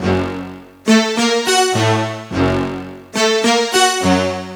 Synth Lick 50-12.wav